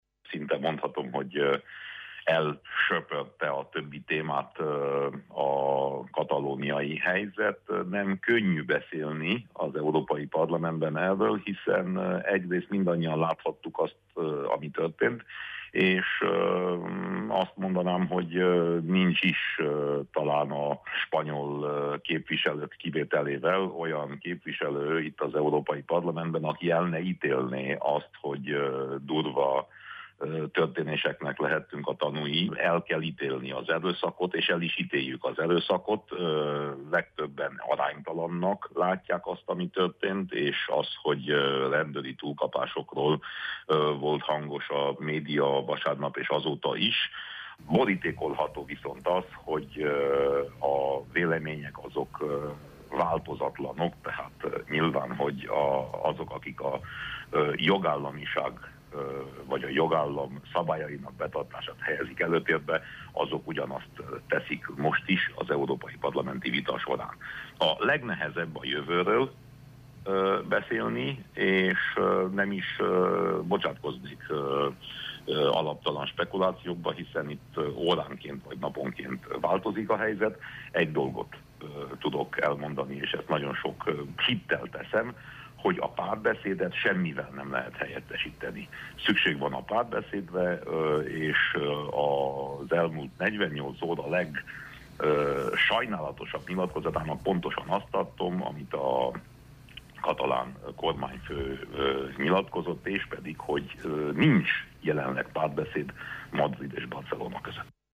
Az interjút teljes terjedelmében a ma délutáni Naprakész című műsorunkban hallgathatják.